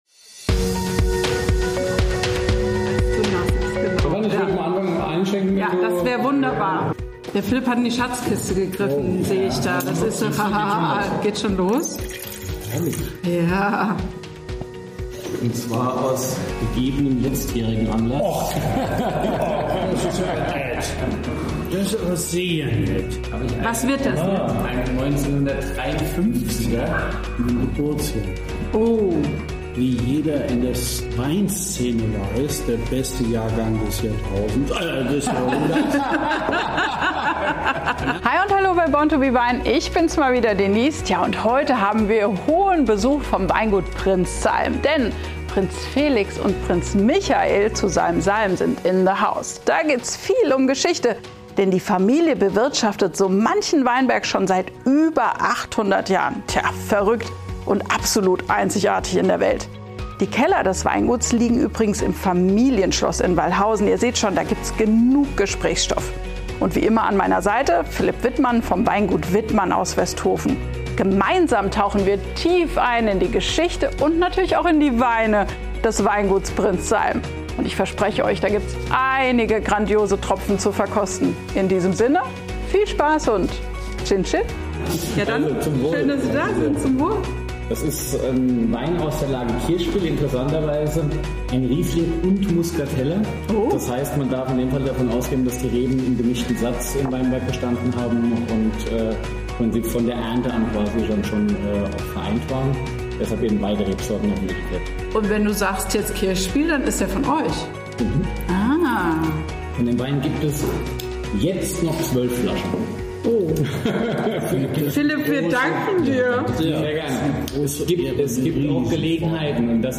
Macht es Euch gemütlich und genießt dieses launige Gespräch mit vielen Geschichten, Anekdoten und spannenden Insights.